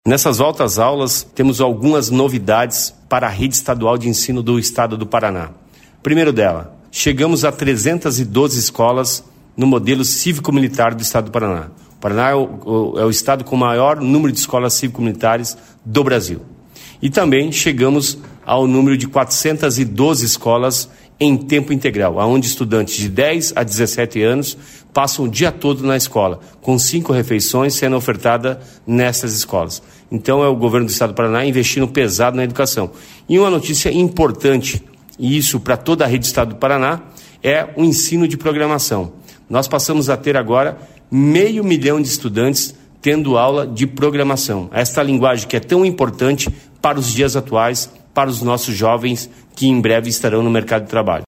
Sonora do secretário da Educação, Roni Miranda, sobre a volta às aulas na rede estadual de ensino